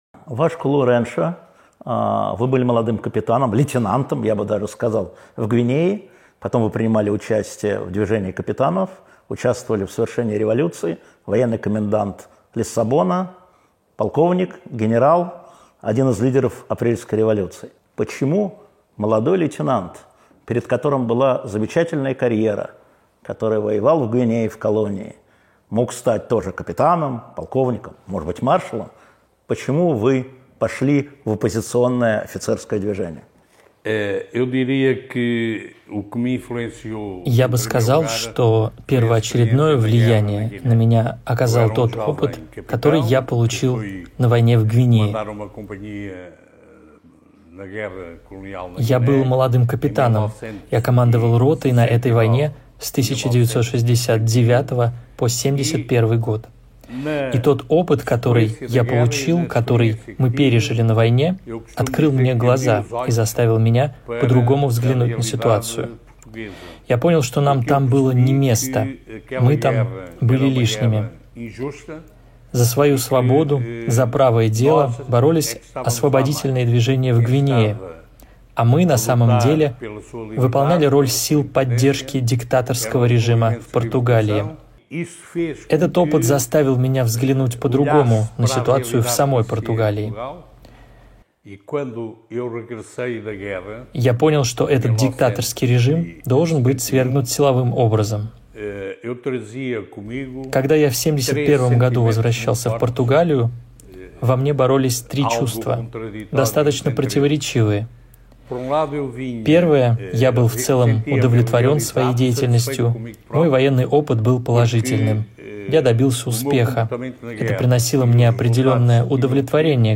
Интервью Алексея Венедиктова / Вашку Лоренсу о революции 25 апреля 24.07.23